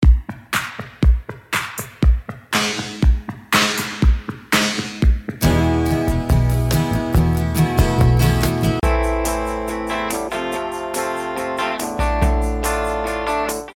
Name the 3 birds in todays kicker in this sneak preview just for our facebook fans!